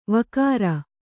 ♪ vakāra